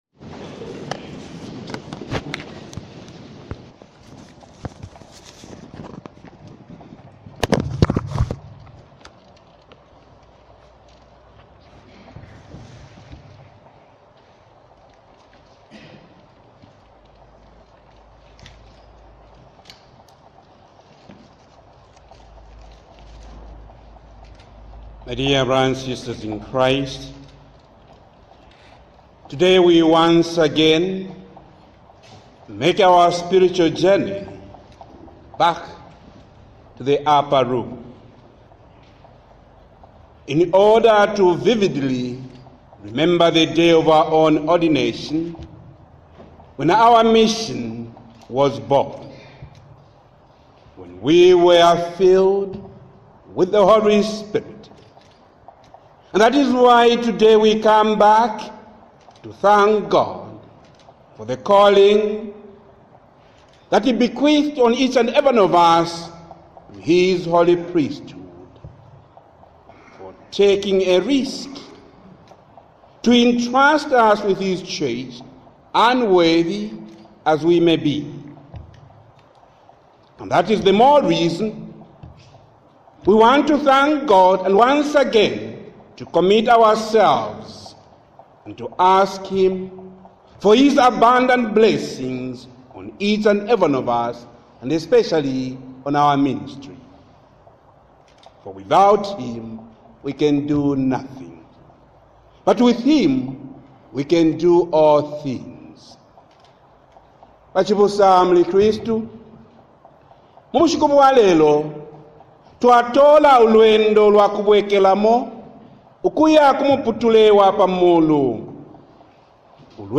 Today 15th April 2019, Ndola diocese celebrated Chrism Mass at St. Joseph Itawa Parish.